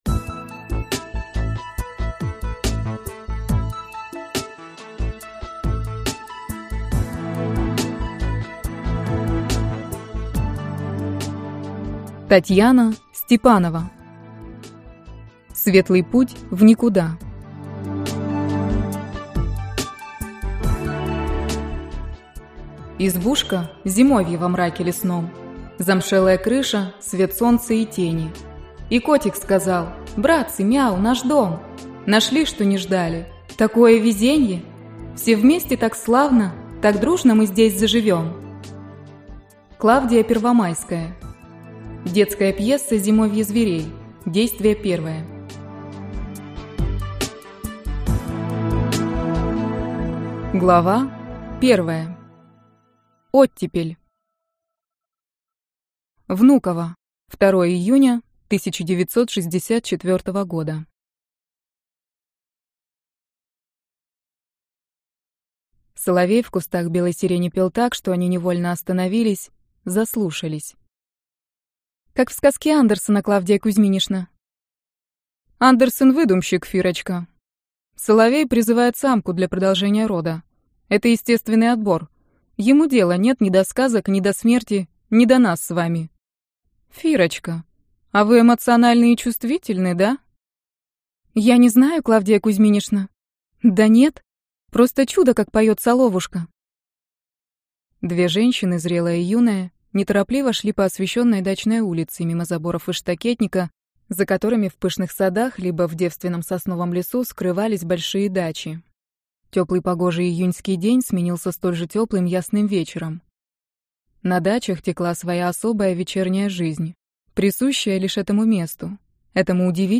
Аудиокнига Светлый путь в никуда - купить, скачать и слушать онлайн | КнигоПоиск